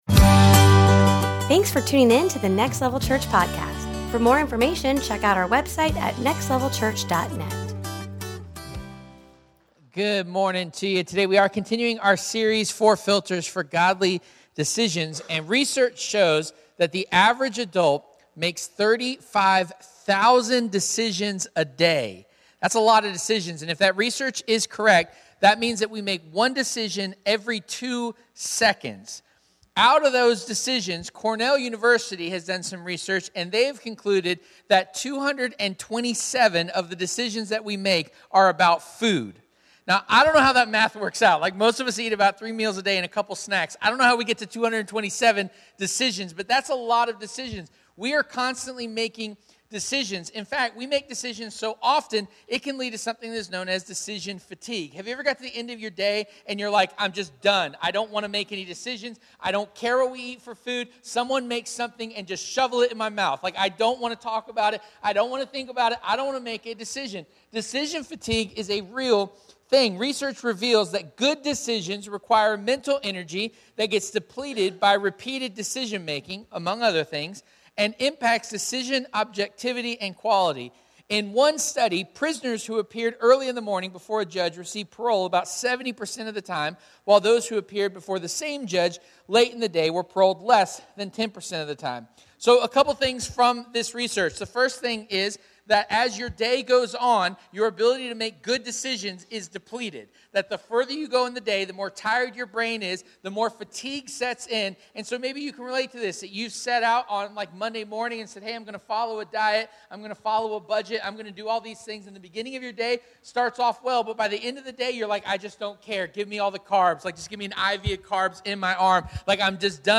4 Filters for Godly Decisions Service Type: Sunday Morning « 4 Filters for Godly Decisions Four Filters For Godly Decisions